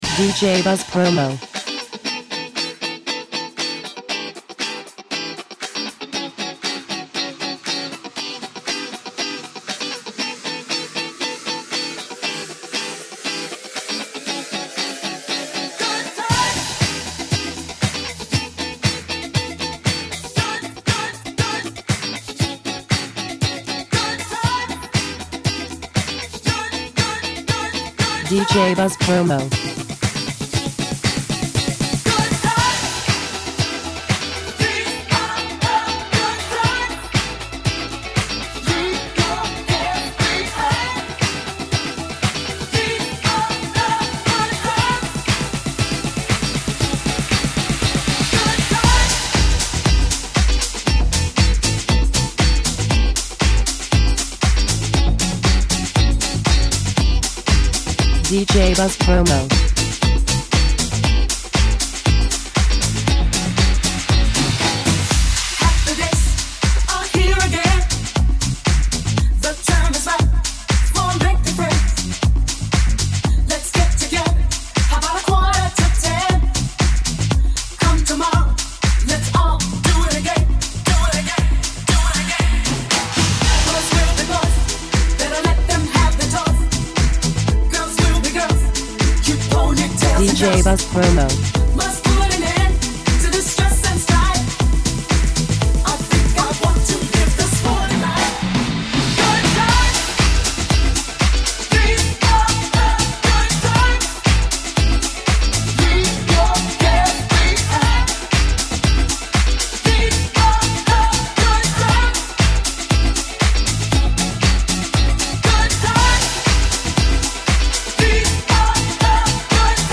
A dive in the Disco of the 70s with this magical remix